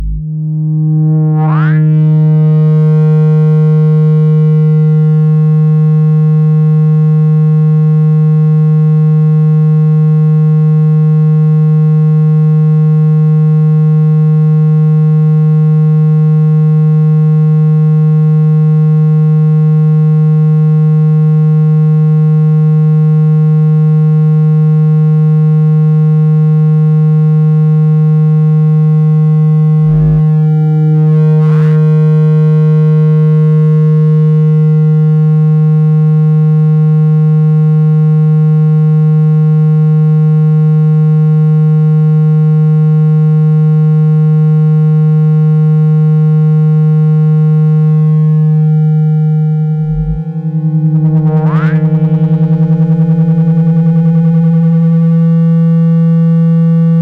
Sound To Remove Water From Phone Speaker.mp3